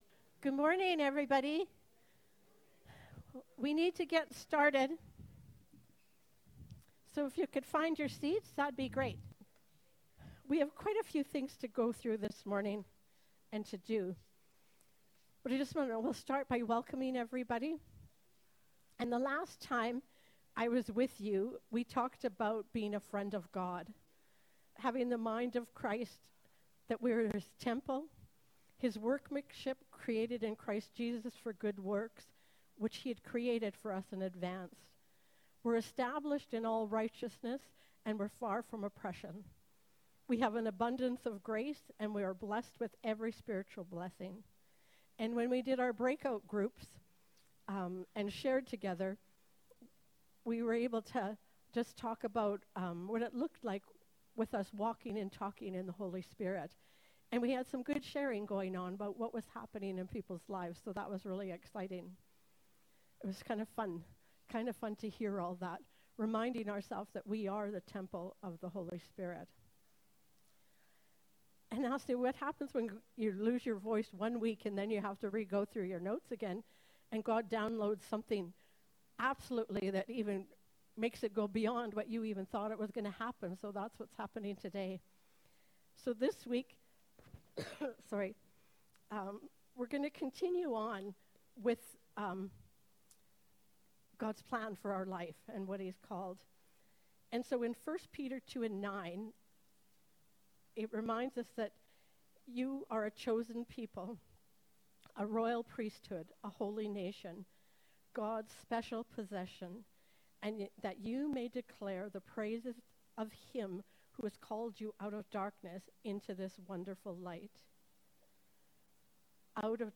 Category: Sunday Morning Live